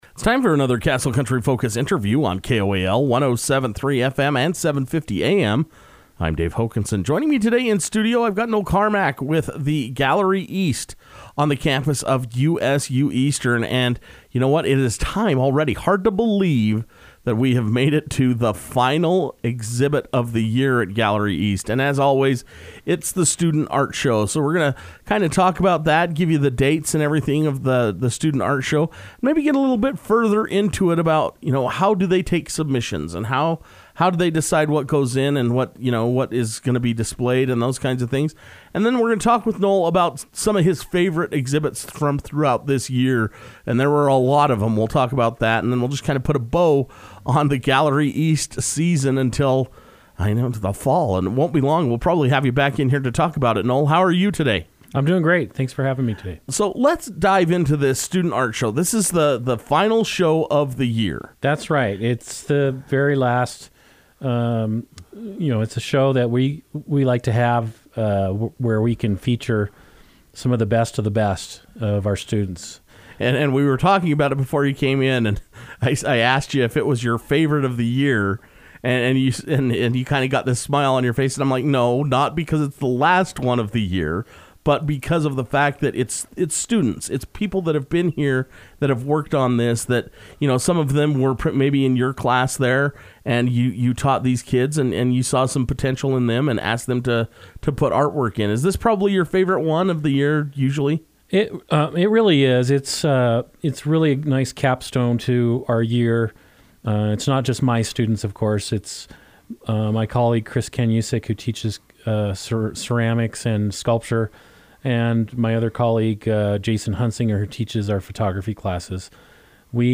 took time to come into Castle Country Radio to discuss all the details.